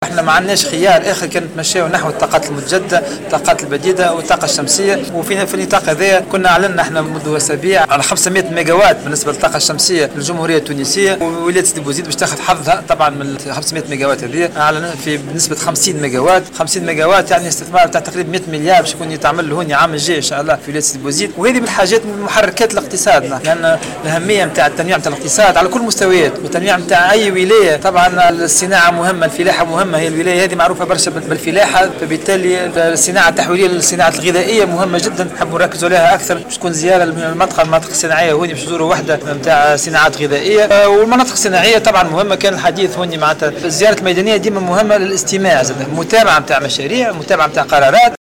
وزير الصناعة و المؤسسات الصغرى و المتوسطة سليم الفرياني في تصريح لمراسل الجوهرة "اف ام" على هامش زيارة عمل إلى ولاية سيدي بوزيد إنها زيارة للإطلاع ومتابعة سير بعض المشاريع في القطاع الصناعي بالجهة.